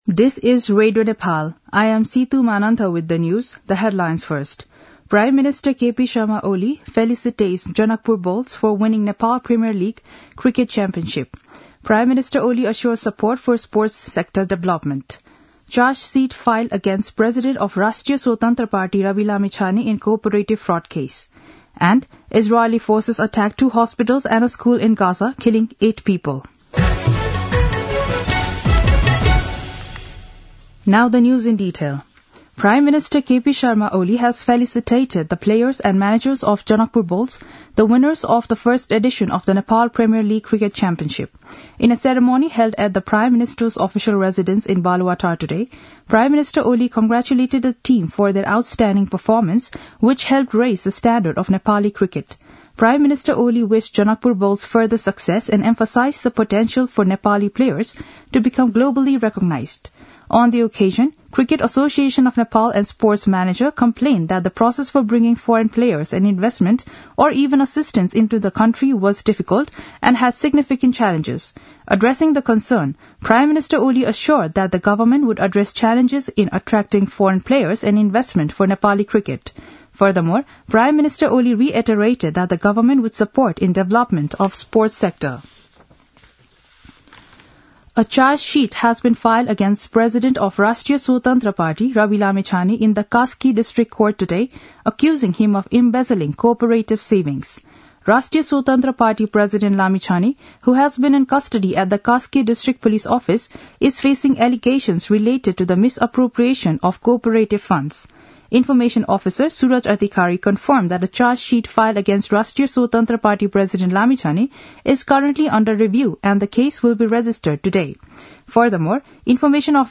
दिउँसो २ बजेको अङ्ग्रेजी समाचार : ८ पुष , २०८१
2-pm-english-news-1-14.mp3